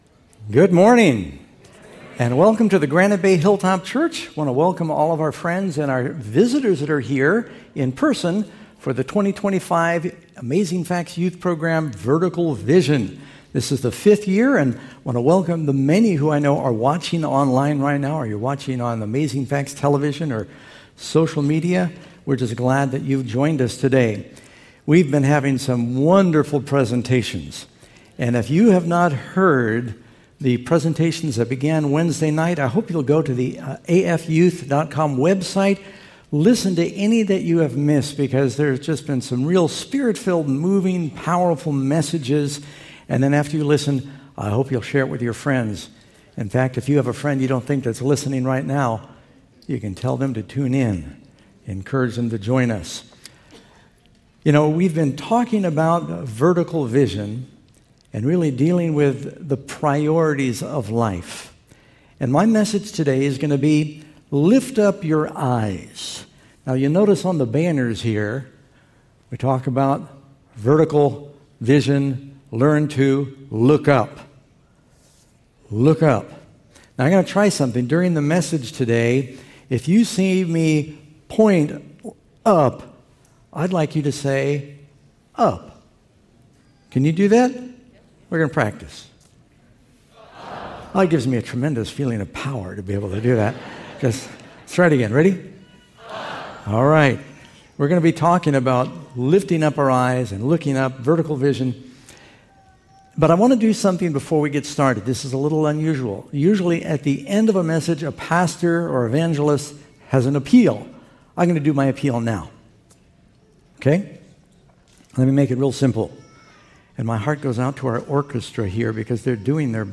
This sermon is part of the Amazing Facts Youth (AFY) conference happening this weekend and will be a blessing for the entire family!